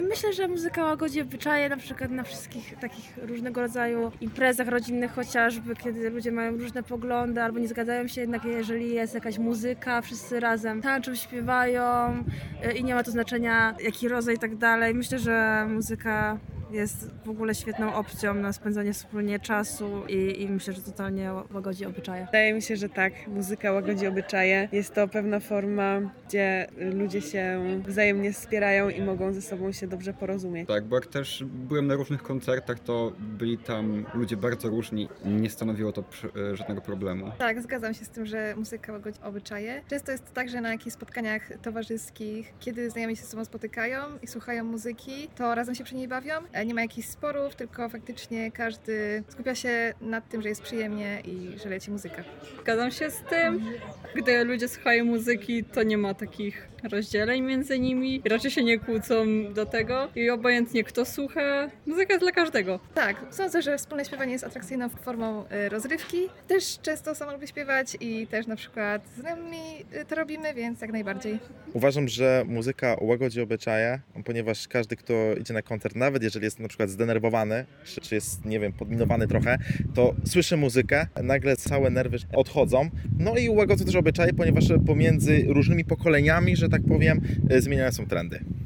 Czy studenci mają podobne zdanie? Czy według nich muzyka łagodzi obyczaje? Posłuchajcie sami:
Jak sami słyszeliście, studenci potwierdzili nasze słowa, a zatem nie pomyliśmy się w tej ocenie.